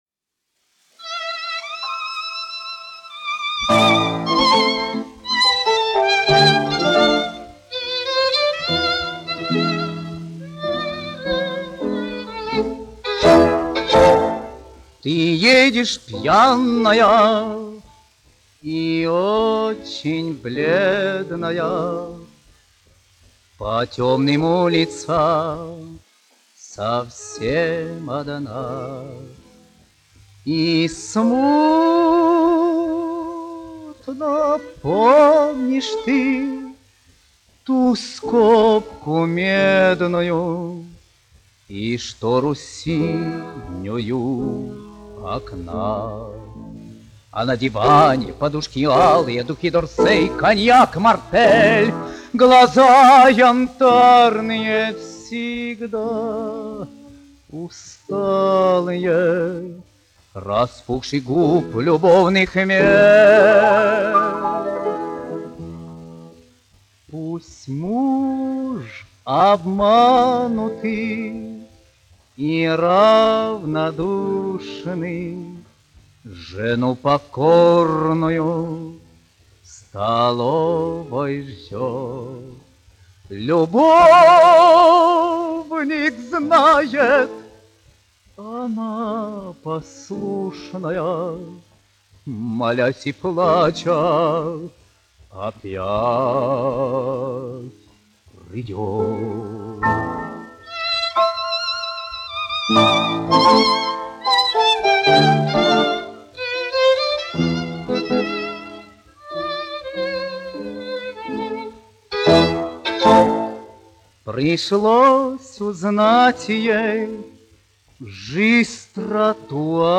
1 skpl. : analogs, 78 apgr/min, mono ; 25 cm
Romances (mūzika)
Skaņuplate